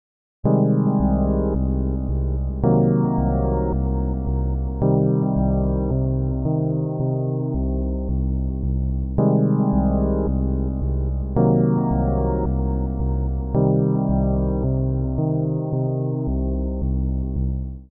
De Mineur 6 en Half Dim-akkoorden uit het laatste geluidsvoorbeeld zouden bijvoorbeeld in de Middeleeuwen als ‘duivels’ of ‘dissonant’ afgeschreven worden en verboden worden door de kerk.